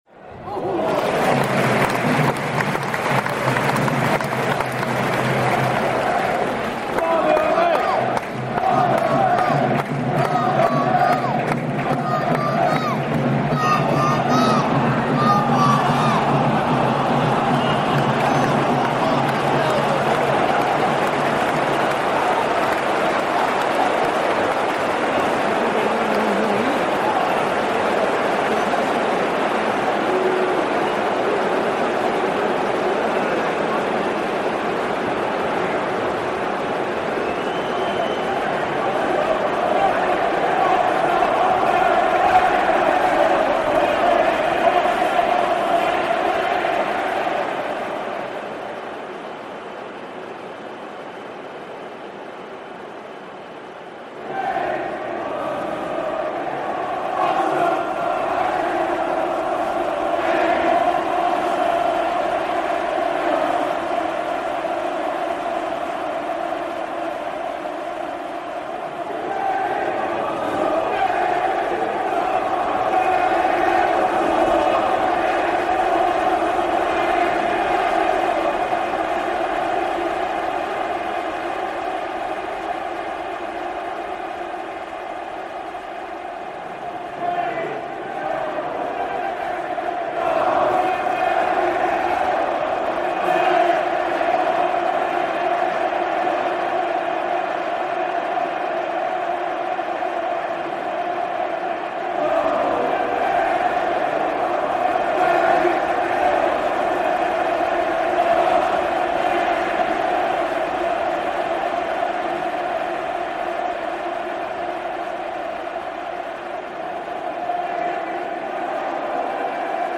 Hillsborough football crowd reimagined by Cities and Memory.
This piece starts with the full football crowd, and uses sound to slowly smear and wipe them away, blurring their chants all over the soundscape like polish onto a mirror. When we've finished cleaning, though, the fans have all disappeared, and we're left with the sound of the game of football being played in an empty stadium, in front of no fans as it is at this moment.